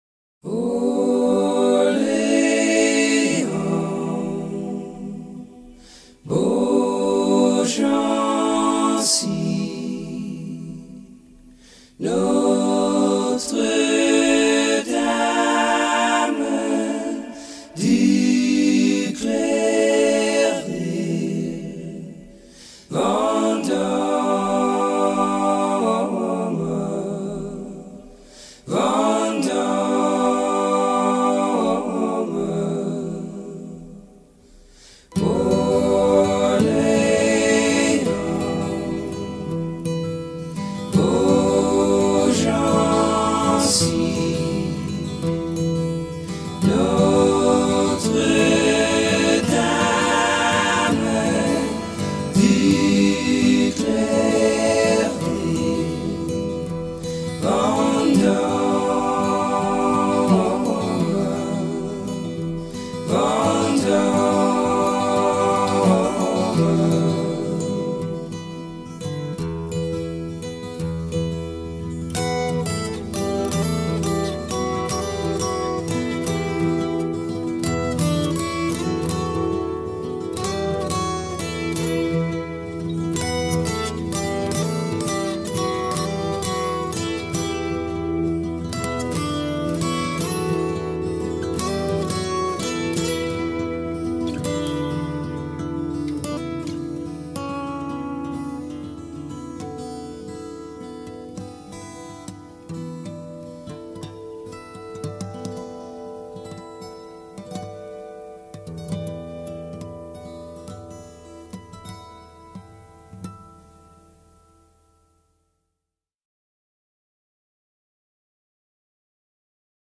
This is a round in French.
It’s a beautiful and delicate song.